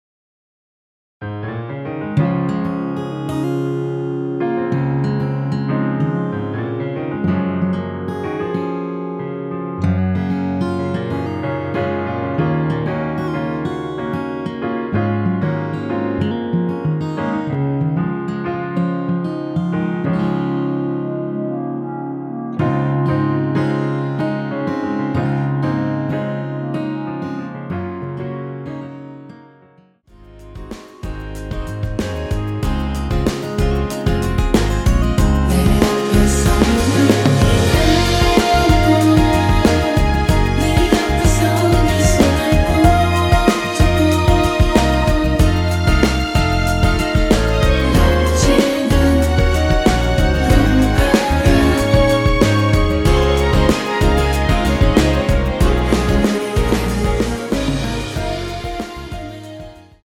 원키 멜로디와 코러스포함된 MR 입니다.(미리듣기 확인)
Db
앞부분30초, 뒷부분30초씩 편집해서 올려 드리고 있습니다.
중간에 음이 끈어지고 다시 나오는 이유는